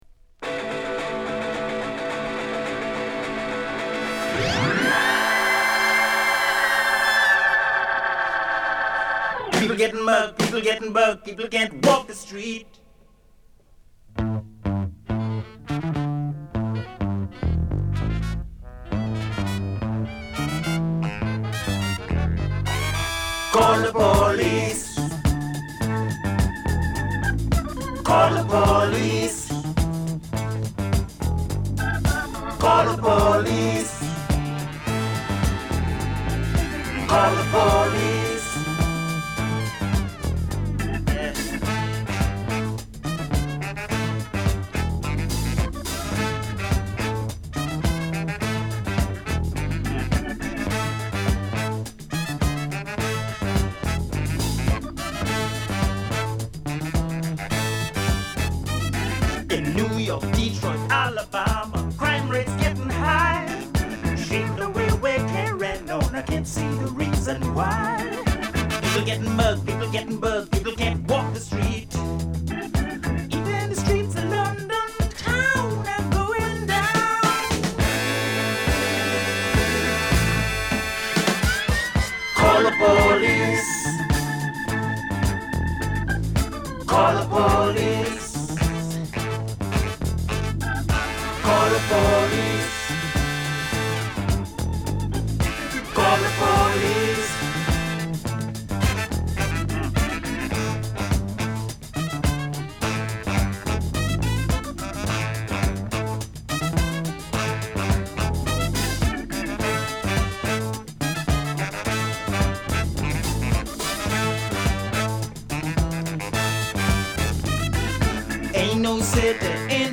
ホーンアレンジやオルガン等が絡むファンキーな一発A3